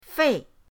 fei4.mp3